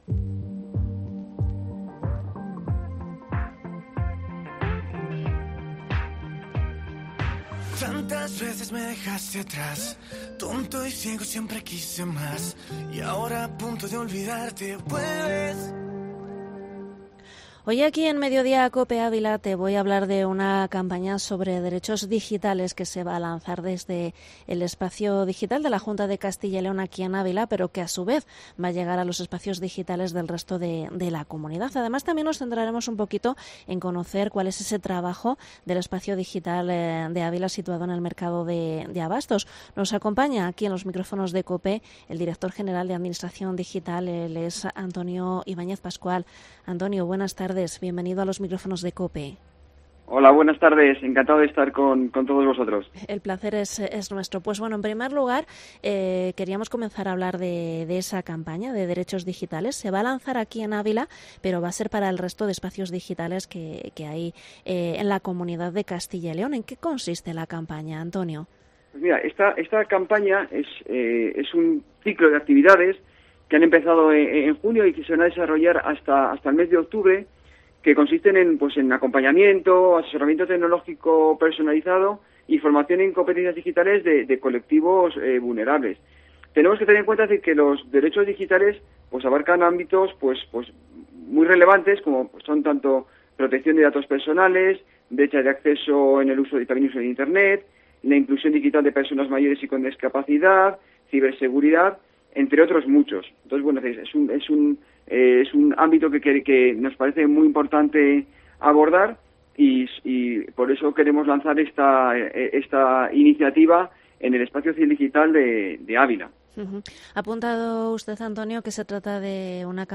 Entrevista al director general de Administración Digital, Antonio Ibáñez